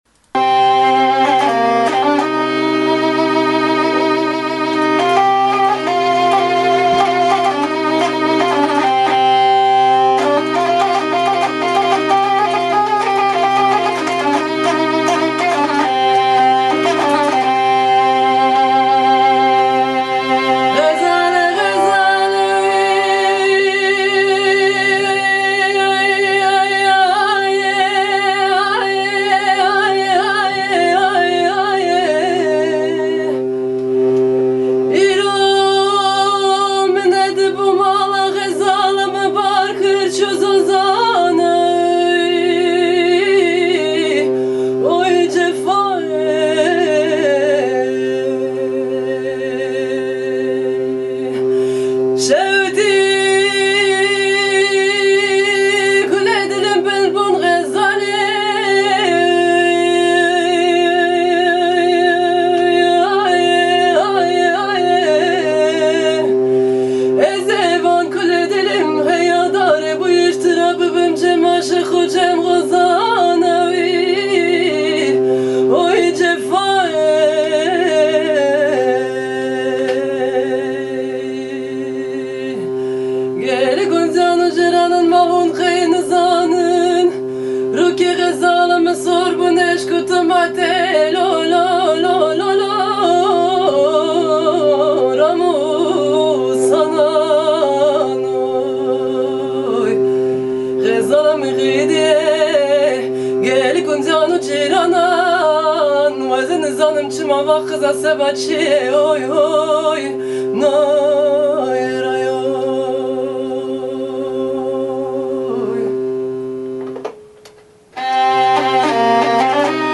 Hortarako, 3 müsikalari badirate ere, Türkia eta ekialdeko müsika tresnekin.
Iganteko kontzertüaren aitzin txestatze bat :